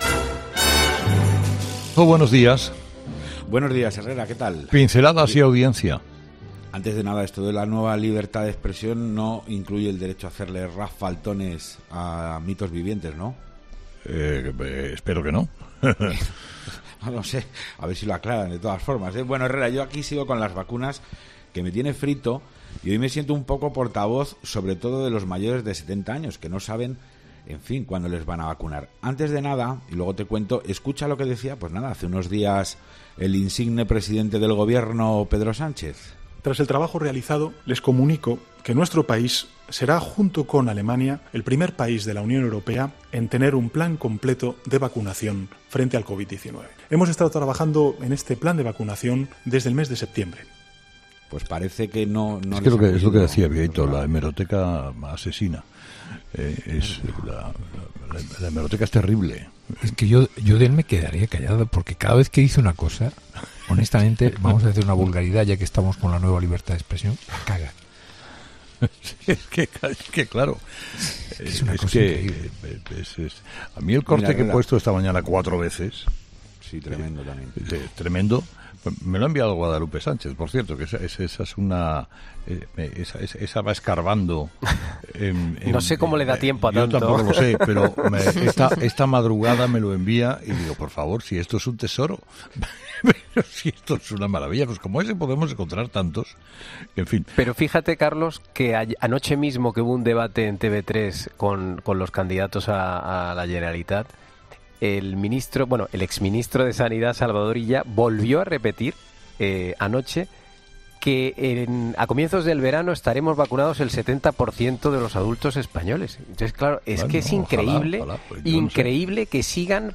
Los oyentes, de nuevo, protagonistas en 'Herrera en COPE' con su particular tertulia.
De 9.30 a 10, antes de que los fósforos tomen el mando, los oyentes de toda España charlan animadamente con Herrera, con los contertulios del día y dan sus opiniones sobre todo aquello que les afecta, emociona, indigna y estimula.